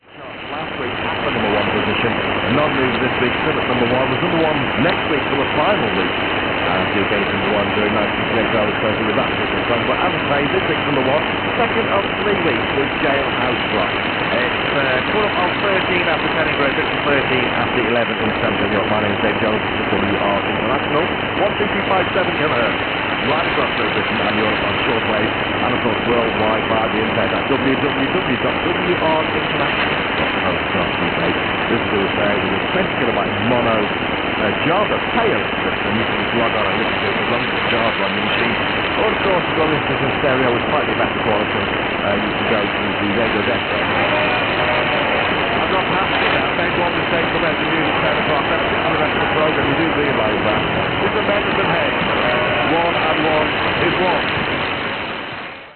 12257 kHz - WR International, UK. Better than ever with my present equipment with even more stable signal. Now I heard their promotion of the web-page.